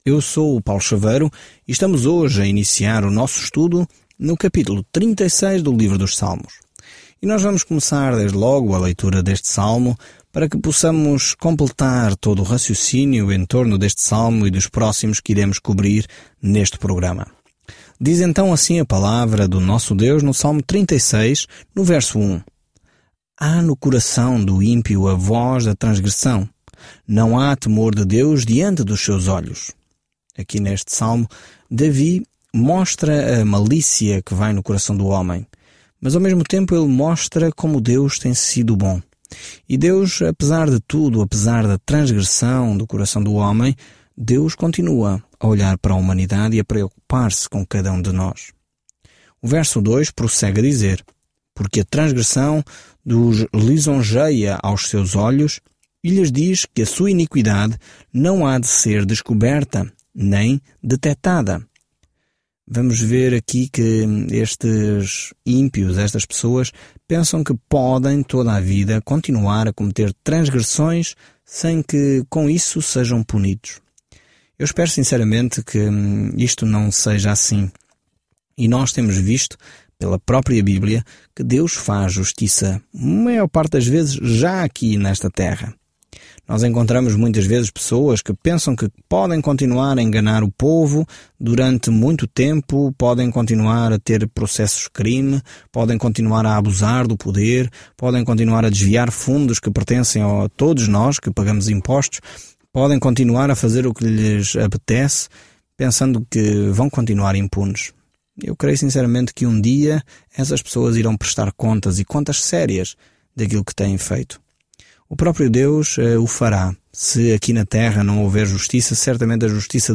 Escritura SALMOS 36 SALMOS 37 SALMOS 38 Dia 21 Iniciar este Plano Dia 23 Sobre este plano Os Salmos nos dão pensamentos e sentimentos de uma série de experiências com Deus; provavelmente cada um foi originalmente musicado. Viaje diariamente pelos Salmos enquanto ouve o estudo de áudio e lê versículos selecionados da palavra de Deus.